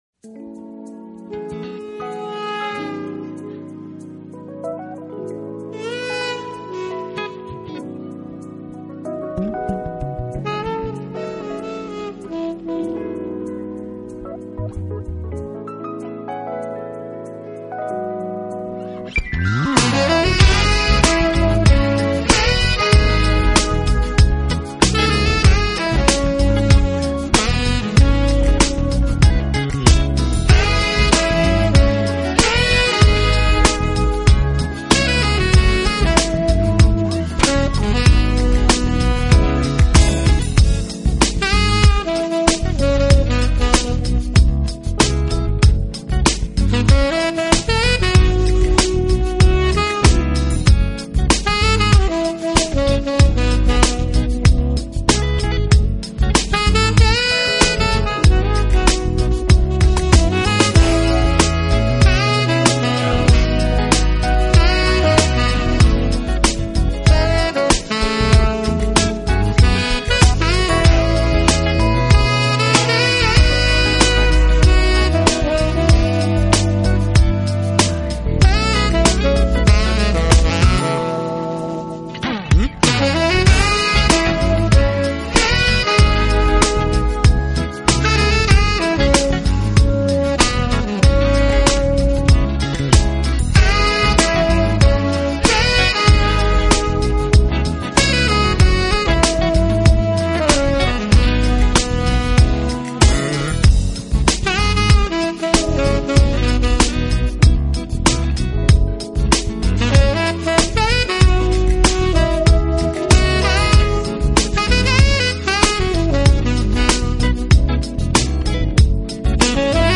当然，黑 人R&B音乐依旧是此专辑的主题思想。